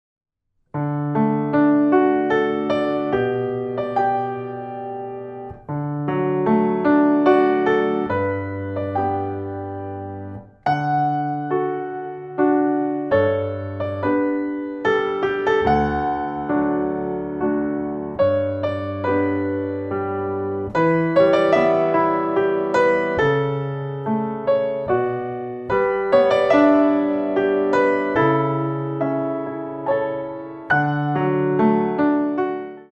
4 bar intro 3/4
64 bars